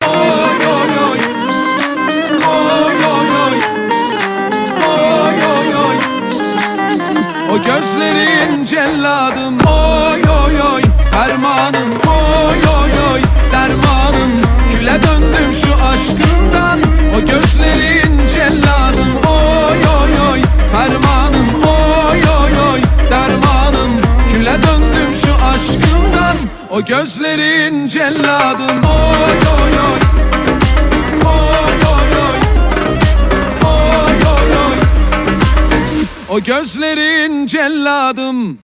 мужской голос
поп , восточные